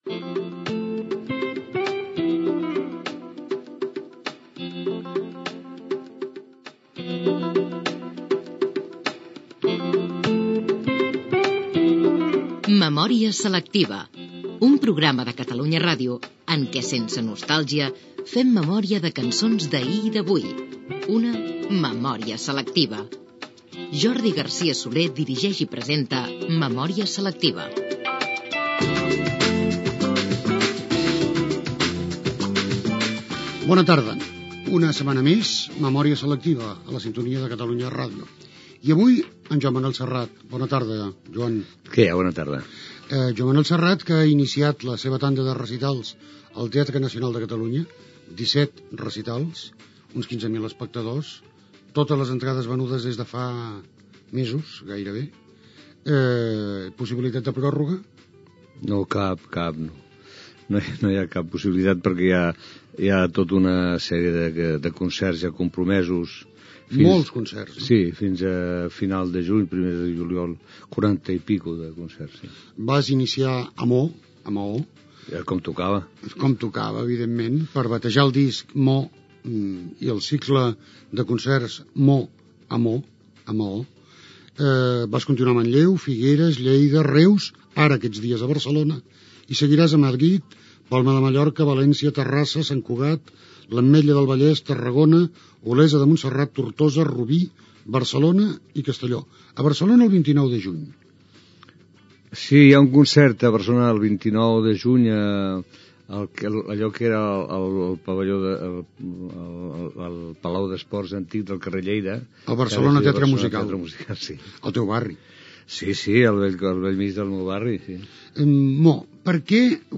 Careta del programa, entrevista al cantant Joan Manel Serrat que actua al Teatre Nacional de Catalunya i presenta el disc "Con ´Mô´ dedicat a Menorca
Entreteniment